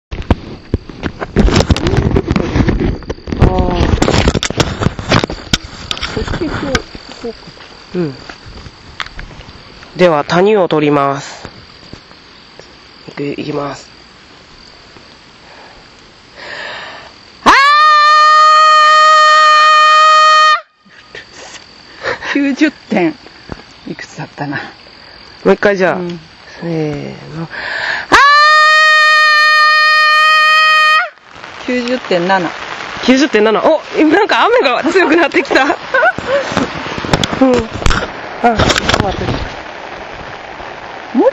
最後に、そんなコツをふまえての渾身の叫びをお聞きいただければ幸いです。
＞＞叫びその１（娘一人バージョン）
叫び場データ「実家ちかくの河原」
大声度91.7デシベル